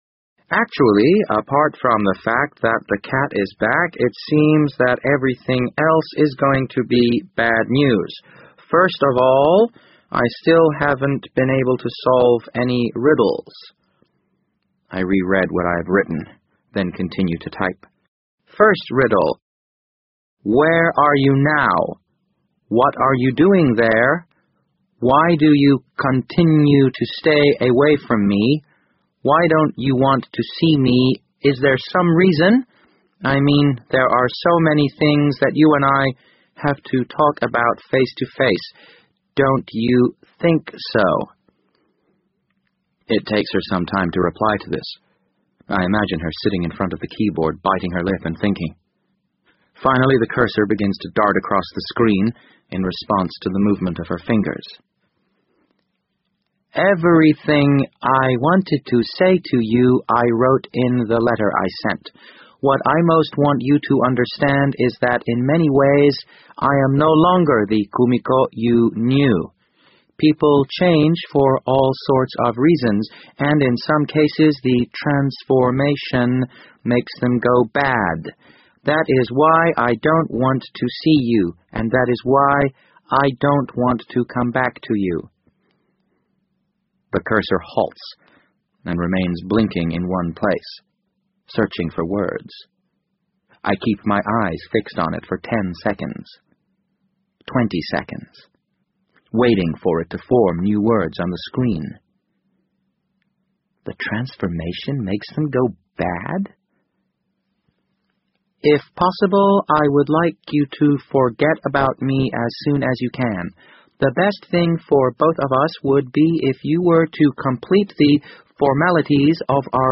BBC英文广播剧在线听 The Wind Up Bird 012 - 16 听力文件下载—在线英语听力室